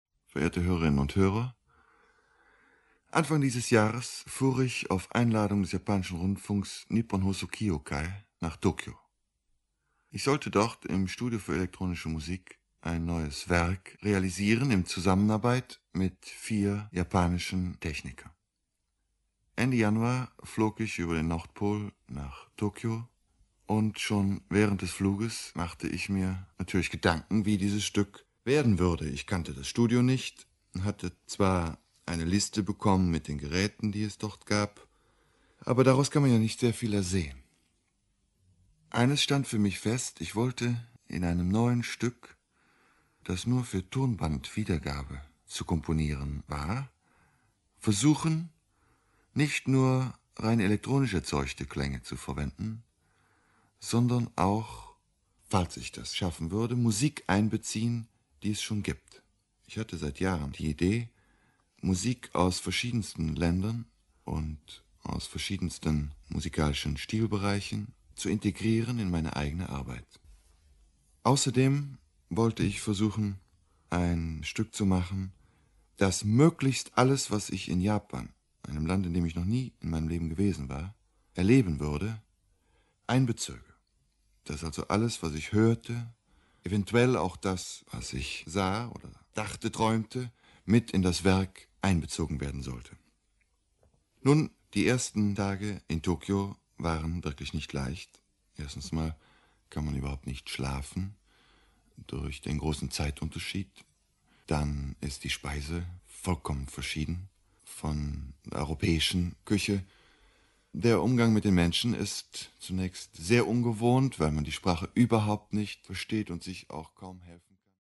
Most of them are supplemented with musical examples.
Lecture 1966